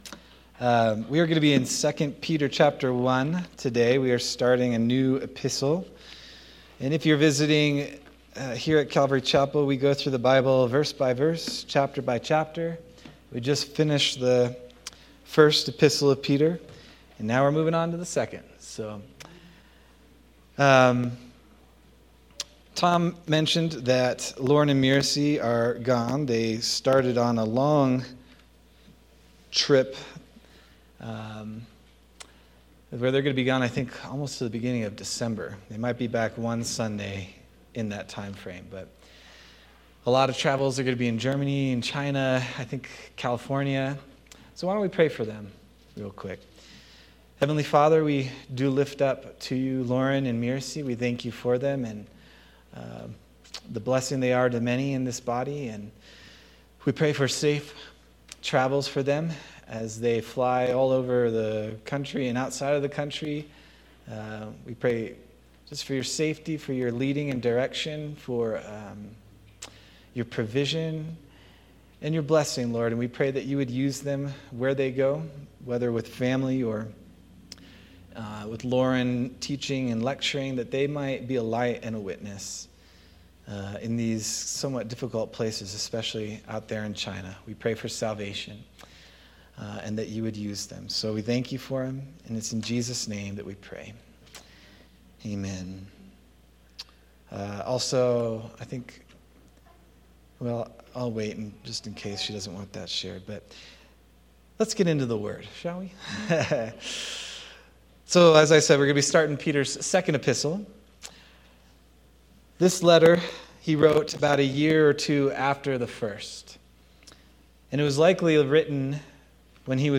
August 31st, 2025 Sermon